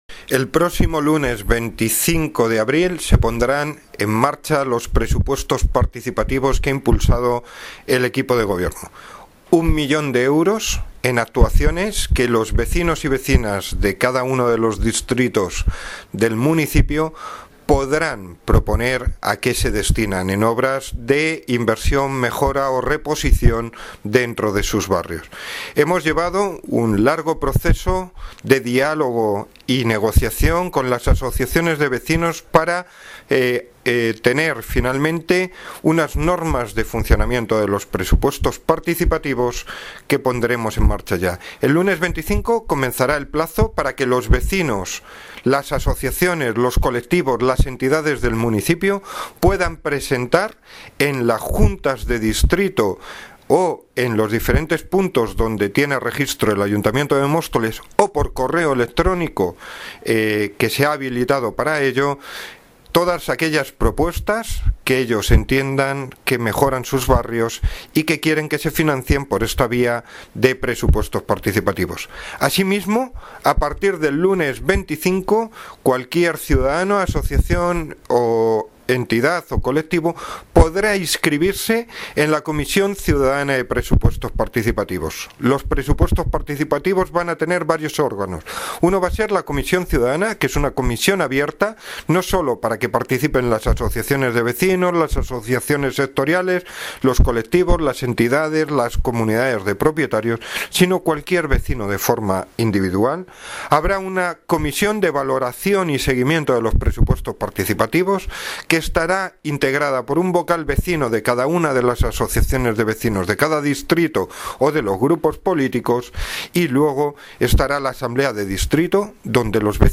Audio - Javier Gómez (Concejal de Hacienda y Patrimonio) Sobre Presupuestos Participativos